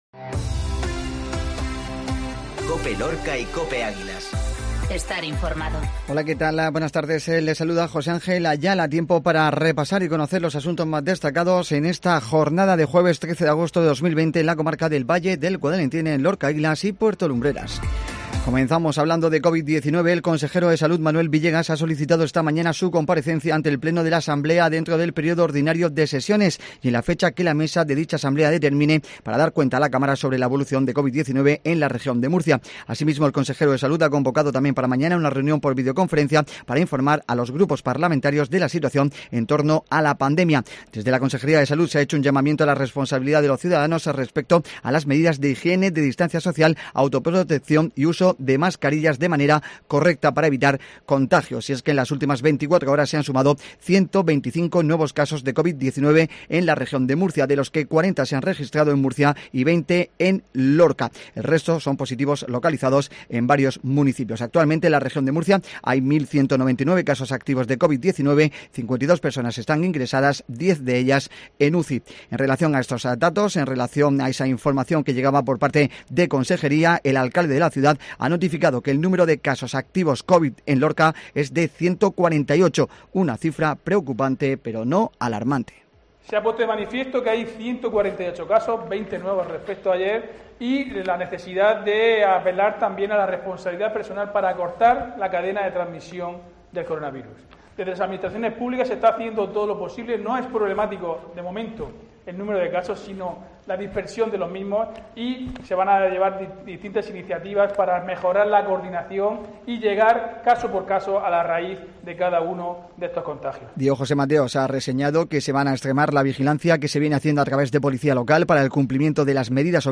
INFORMATIVO MEDIODÍA COPE